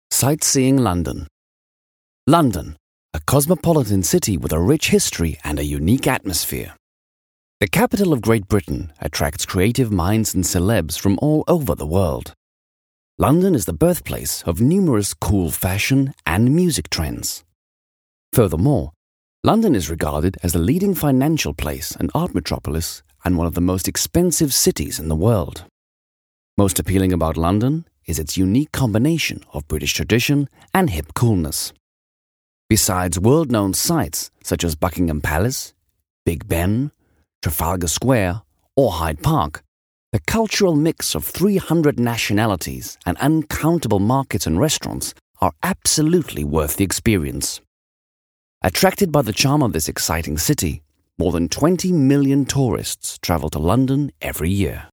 Sprecher kabine mit Neumann U87 AI Joe Meek Pre amp Logic Software Music Taxi /APT-X
britisch
Sprechprobe: Industrie (Muttersprache):
Warm, authoritative sound - ideal for commercials and presentation.
Very flexible voice extremely well suited for animation and character work